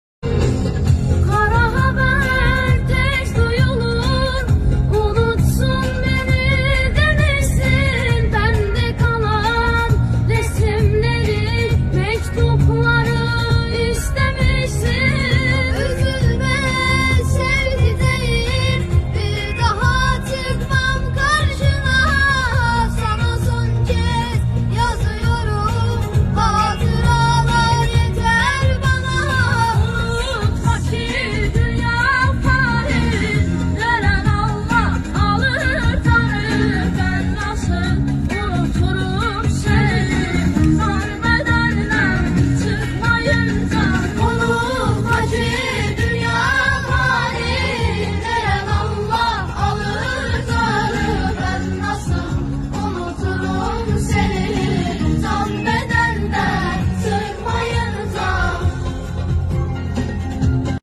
Azeri Sarki Soyleyen Cocuklar